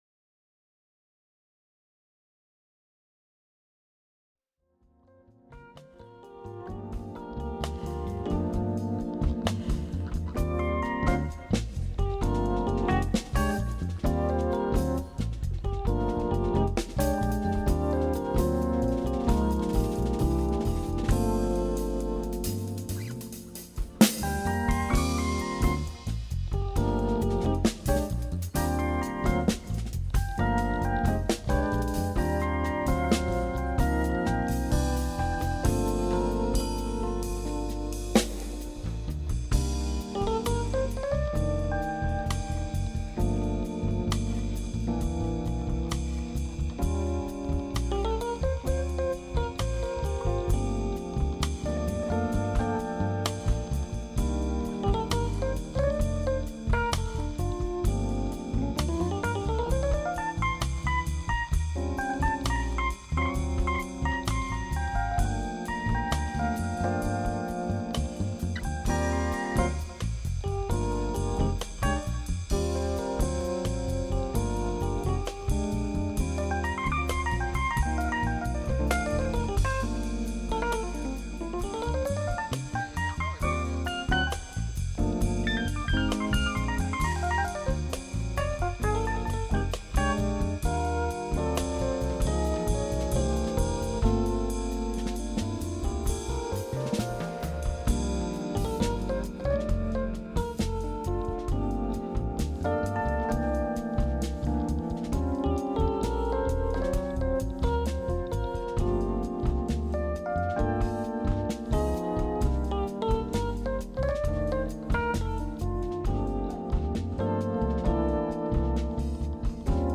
Genre: Jazz/Latin.